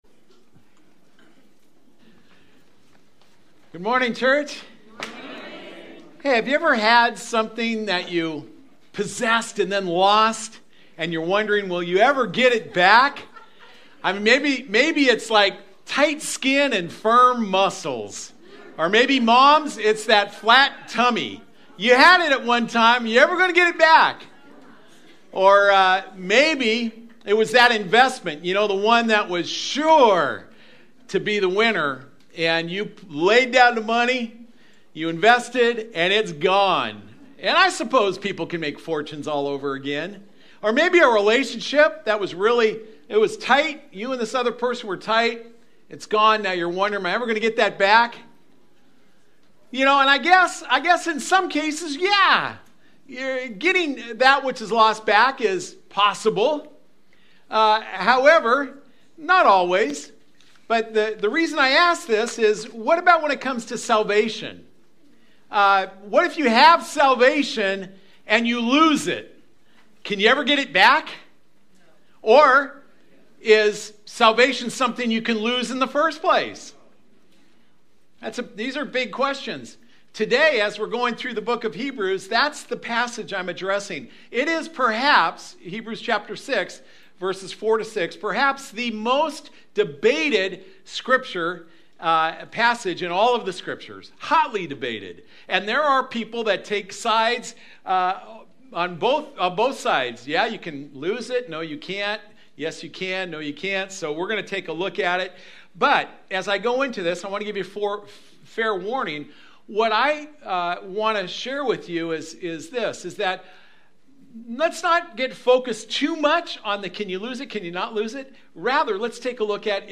A message from the series "A Better Way."